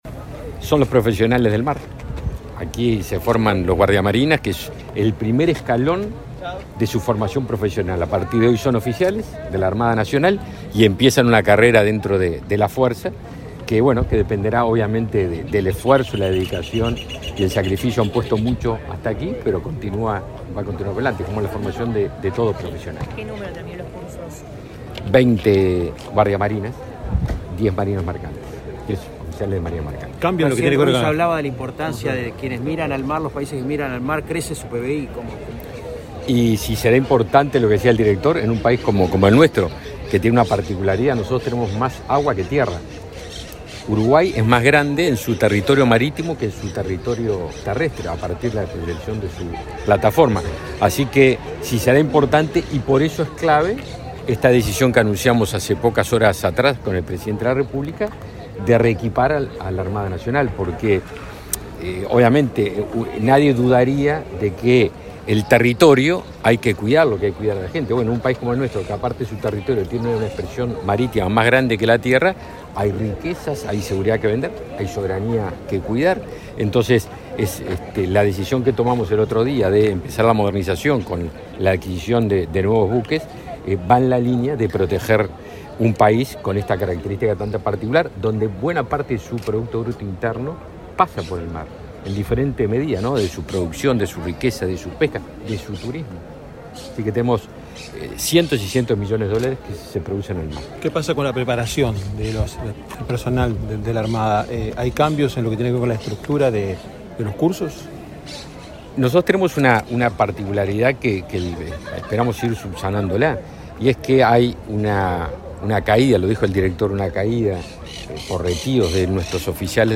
Declaraciones a la prensa del ministro de Defensa, Javier García
Declaraciones a la prensa del ministro de Defensa, Javier García 20/12/2021 Compartir Facebook X Copiar enlace WhatsApp LinkedIn El ministro de Defensa, Javier García, participó este lunes 20 en Montevideo en la ceremonia de fin de cursos de la Escuela Naval y, luego, dialogó con la prensa.